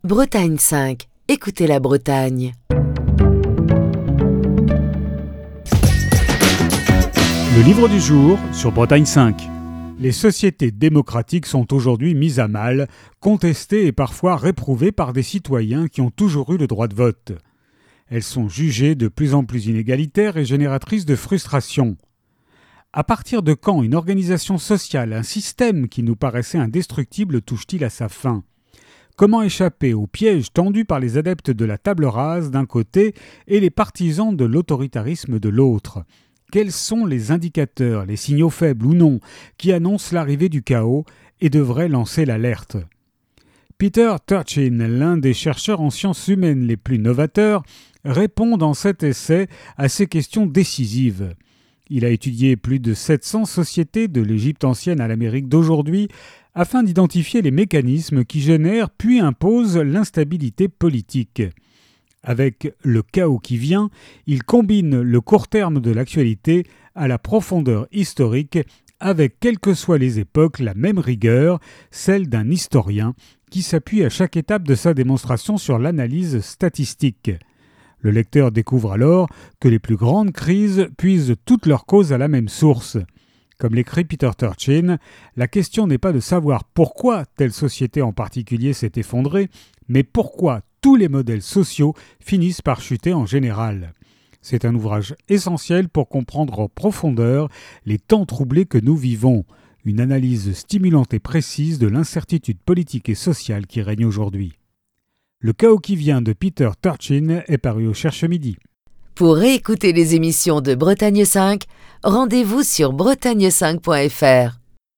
Chronique du 15 janvier 2025.